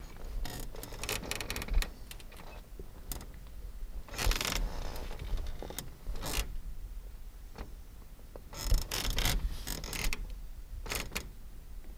Bed Squeaks Various